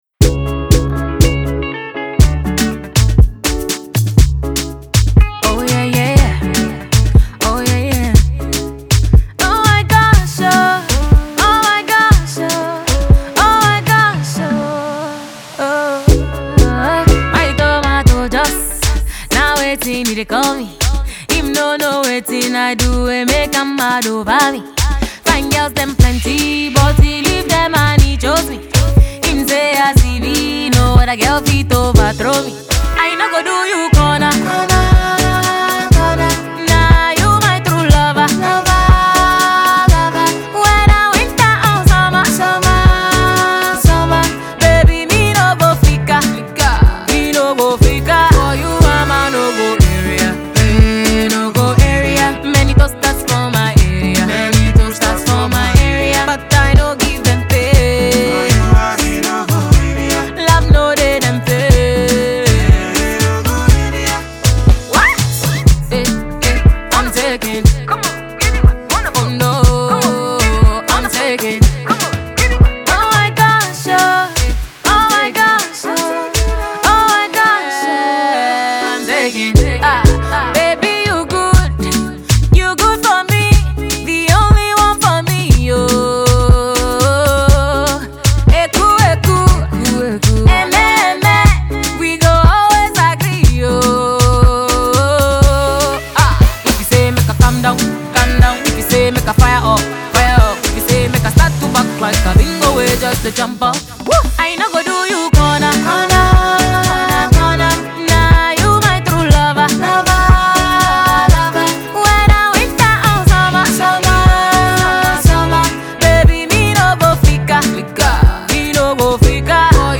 highlife